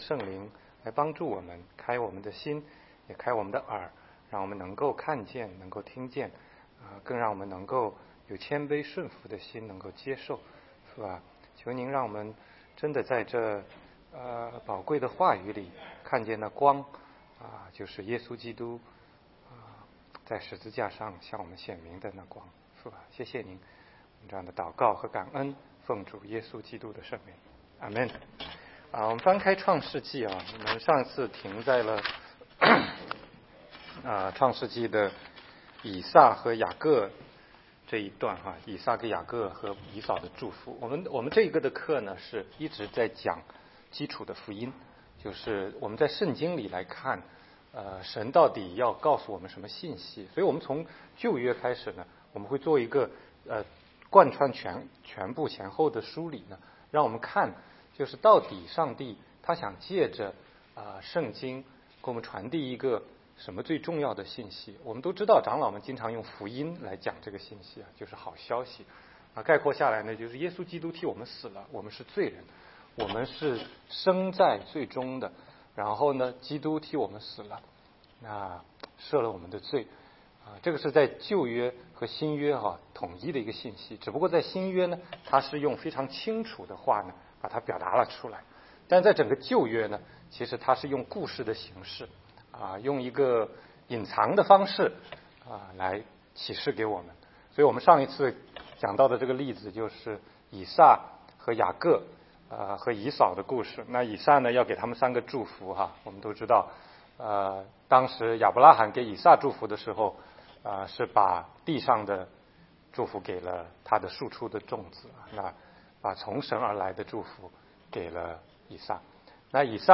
16街讲道录音 - 雅各一生的启示